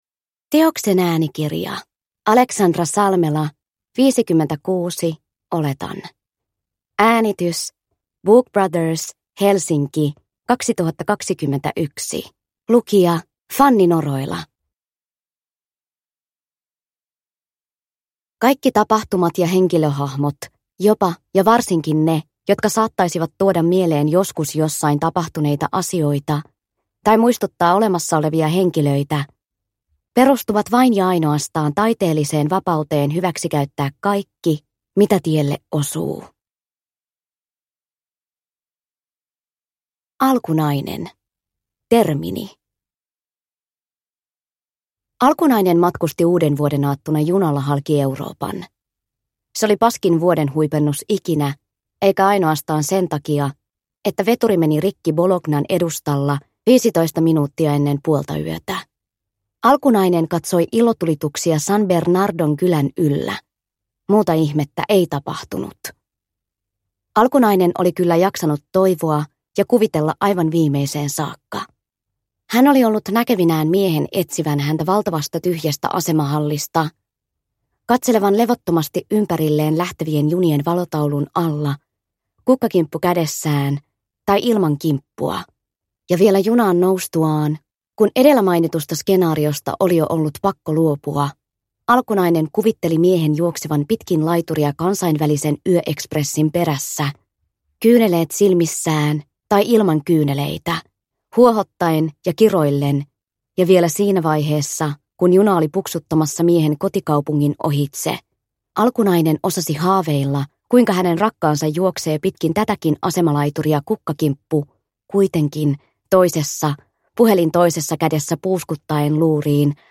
56, oletan – Ljudbok – Laddas ner